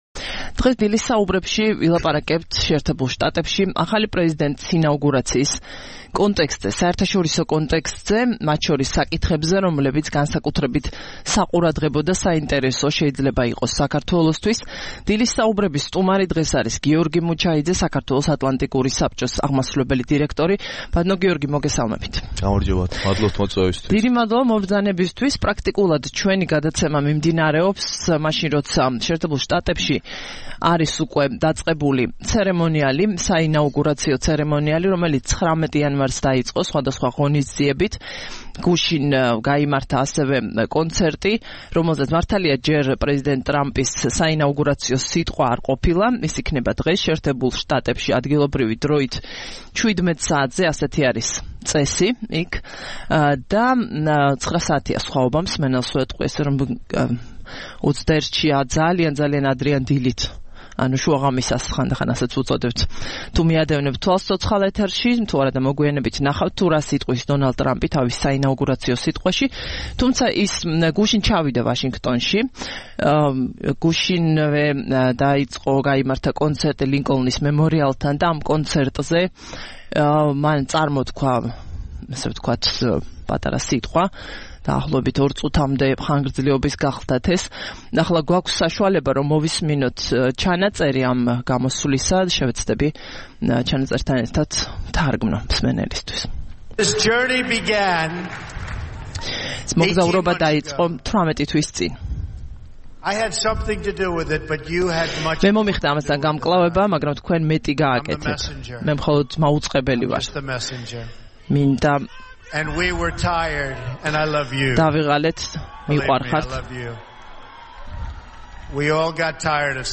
სტუმრად ჩვენს ეთერში